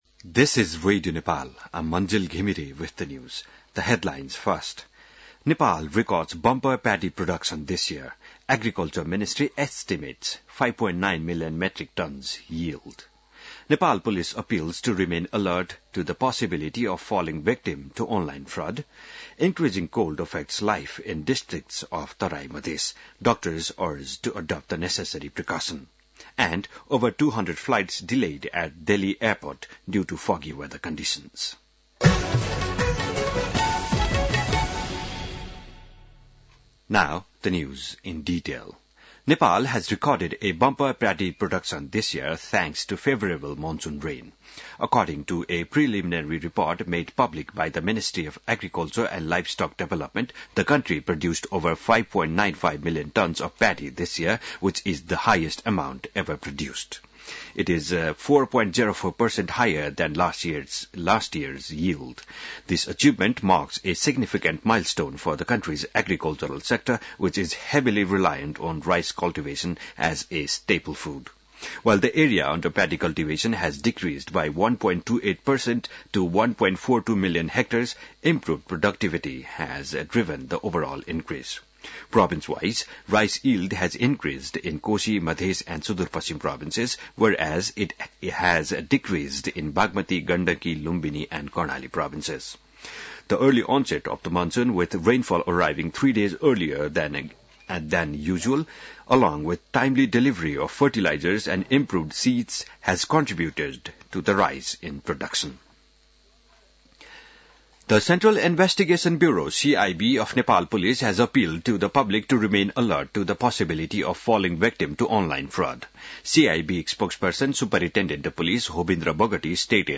बिहान ८ बजेको अङ्ग्रेजी समाचार : २१ पुष , २०८१